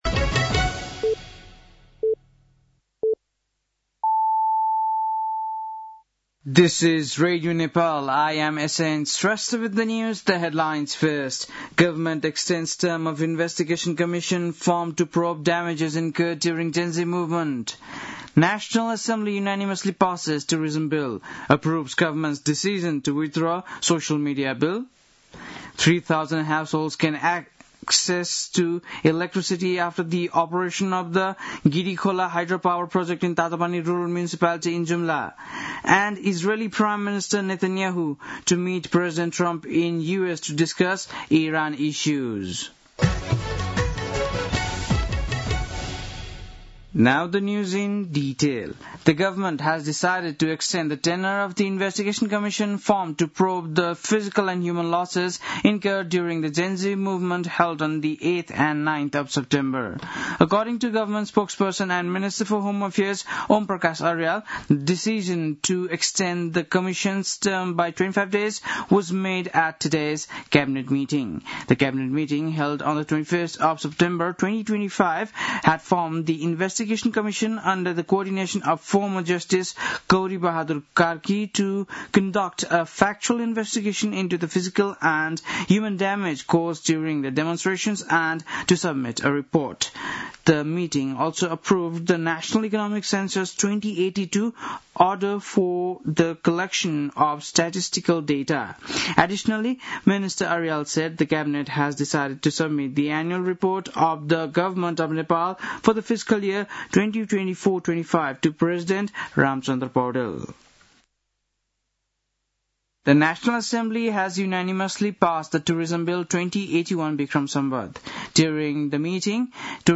बेलुकी ८ बजेको अङ्ग्रेजी समाचार : २६ माघ , २०८२
8-pm-english-news-10-26.mp3